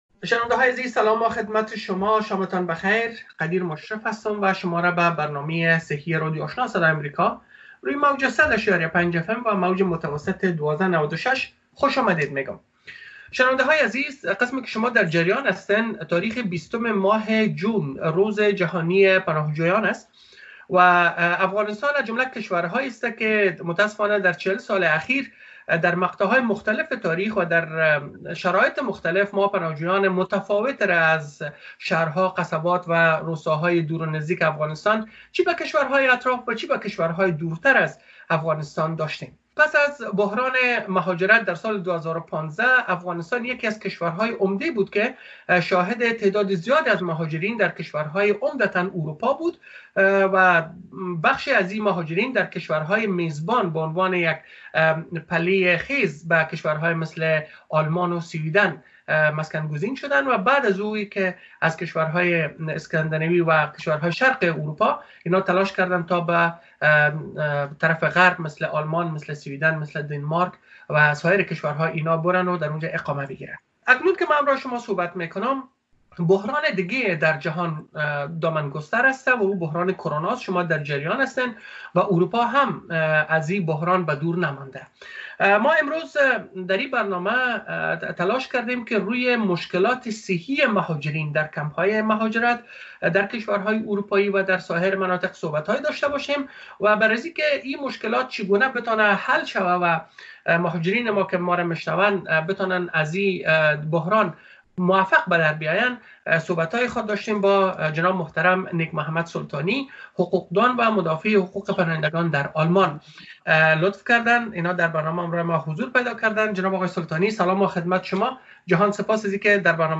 جریان مصاحبه